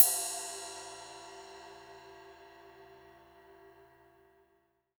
D2 RIDE-02-R.wav